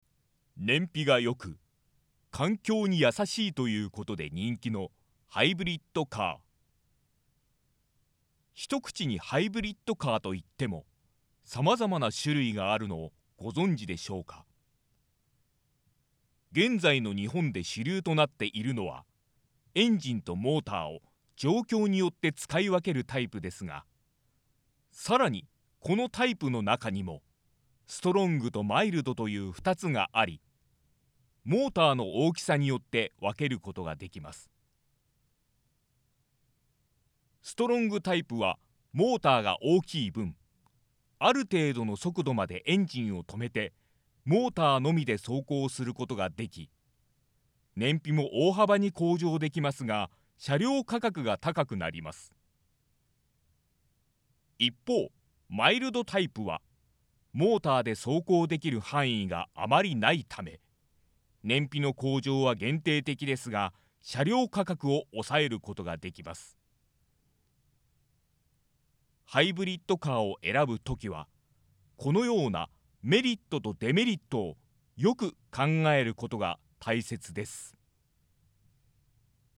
音声サンプル
年齢不詳（？）の柔らかく優しい声が持ち味です。